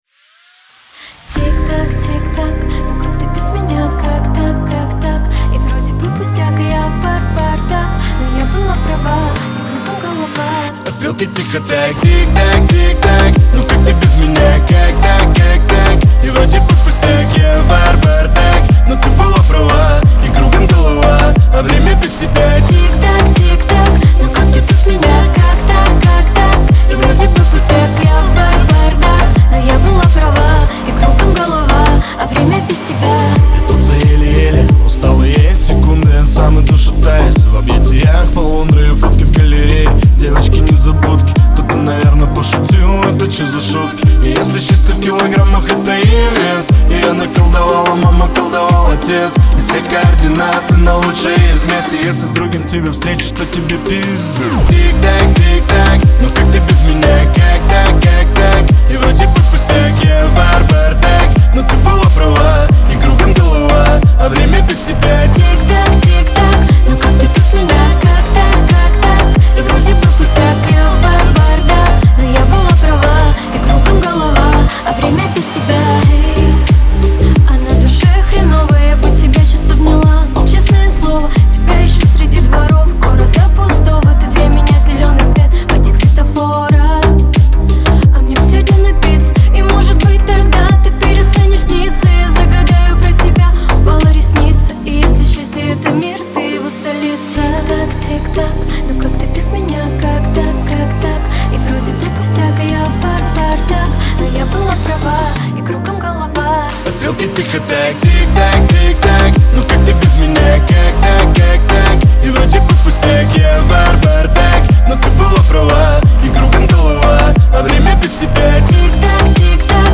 Лирика
pop , дуэт